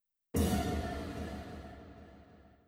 Jump Scare Effect.wav